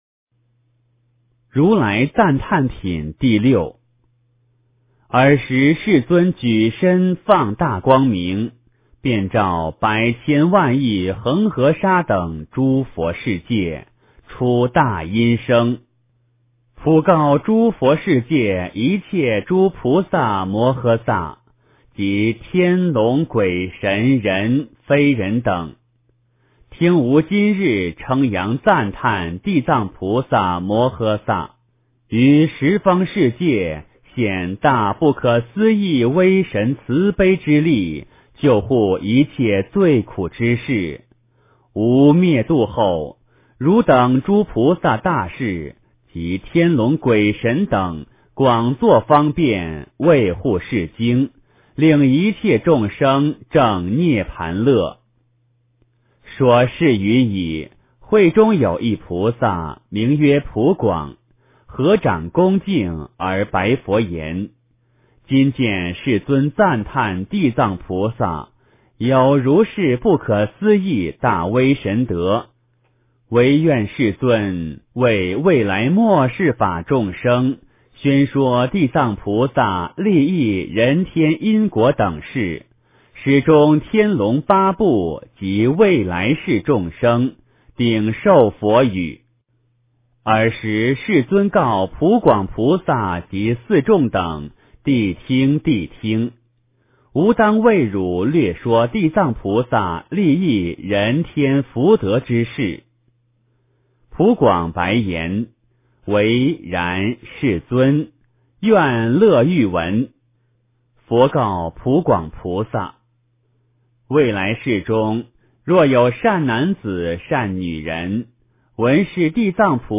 地藏经-如来赞叹品第六 诵经 地藏经-如来赞叹品第六--佛经 点我： 标签: 佛音 诵经 佛教音乐 返回列表 上一篇： 地藏经-分身集会品第二 下一篇： 八十八佛大忏悔文 相关文章 心经 心经--湘佛莲...